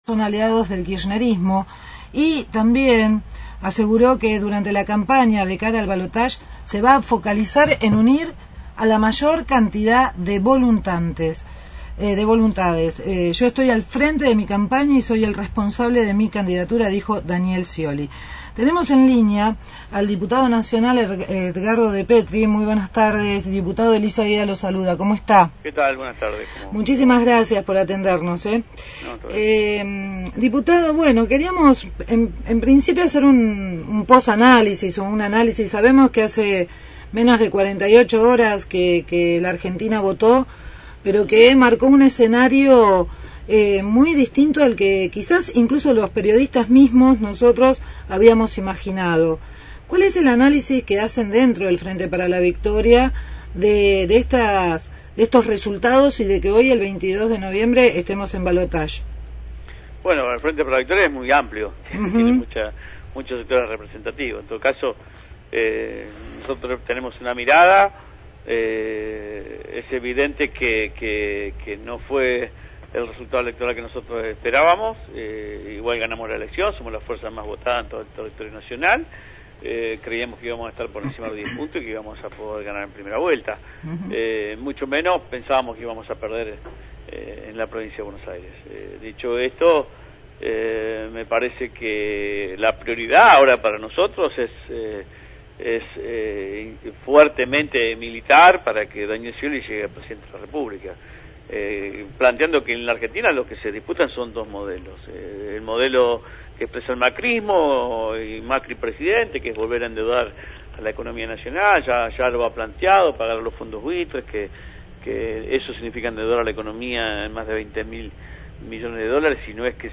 Entrevista a Edgardo Depetri, Diputado Nacional por el Frente para la Victoria sobre el panorama político después de las elecciones.